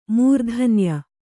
♪ mūrdhanya